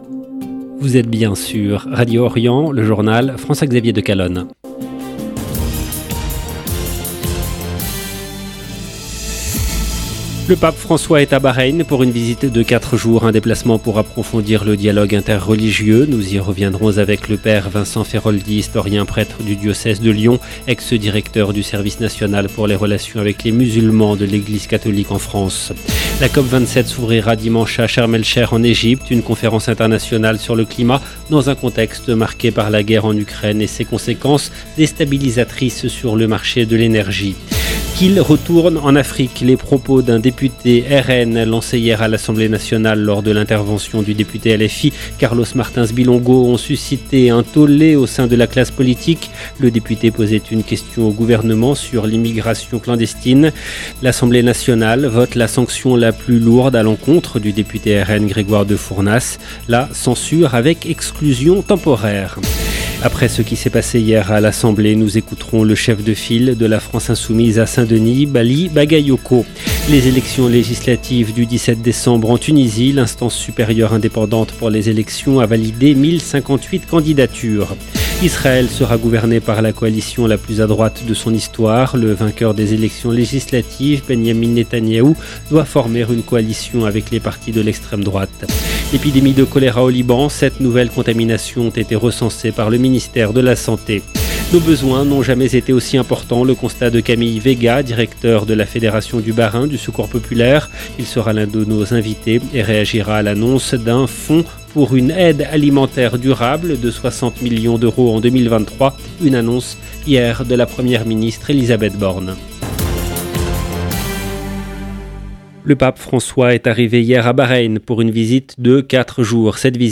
EDITION DU JOURNAL DU SOIR EN LANGUE FRANCAISE DU 4/11/2022